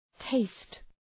Προφορά
{teıst}